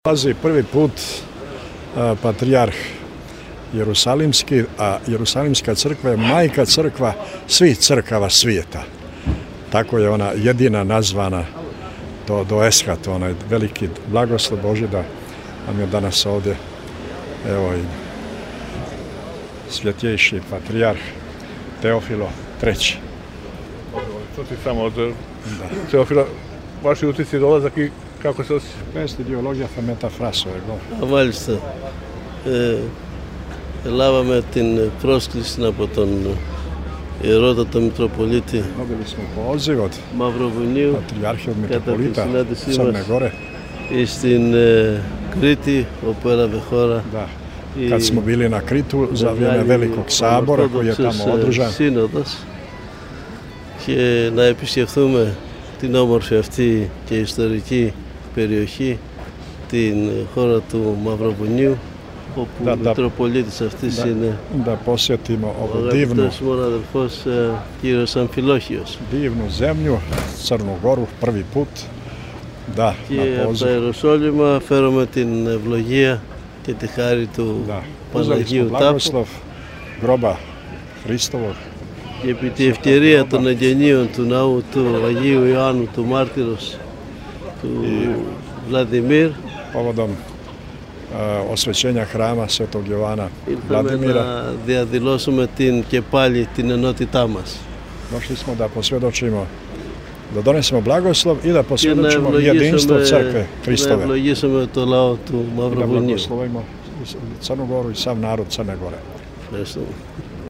По доласку Патријарха Теофила у Тиват Високопреосвећени Митрополит Г. Амфилохије је дао следећу изјаву.